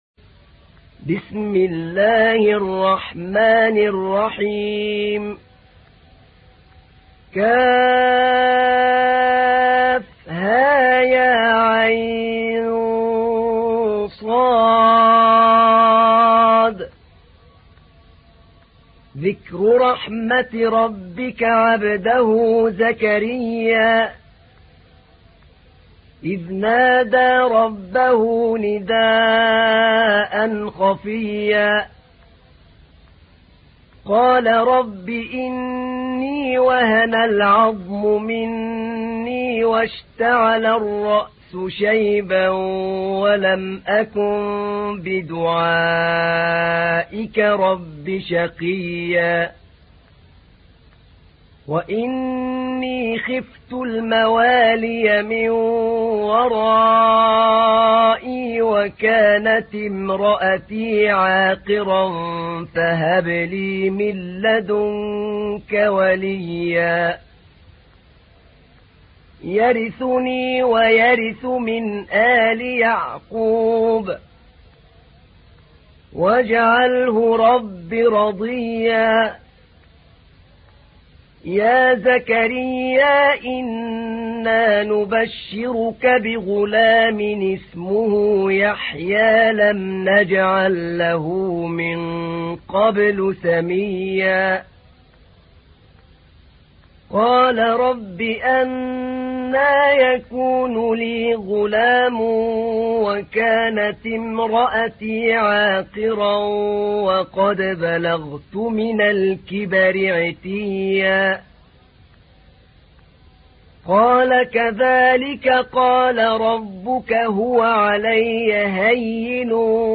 تحميل : 19. سورة مريم / القارئ أحمد نعينع / القرآن الكريم / موقع يا حسين